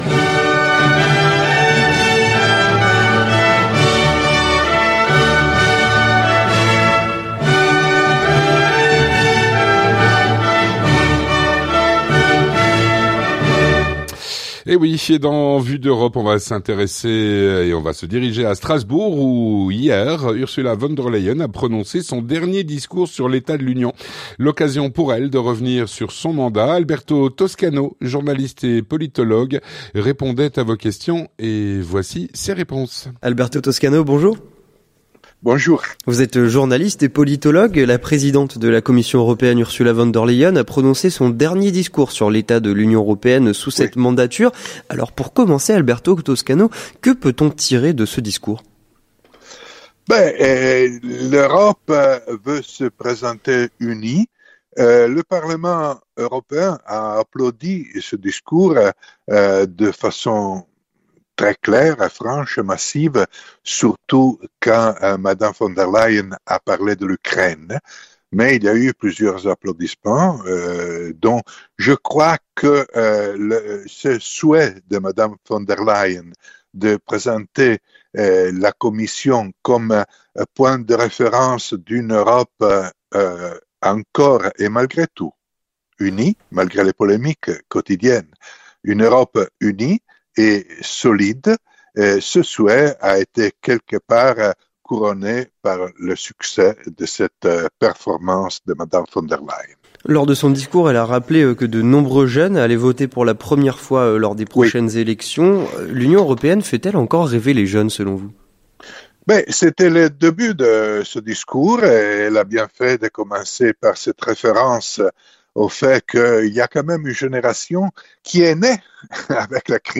journaliste et politologue.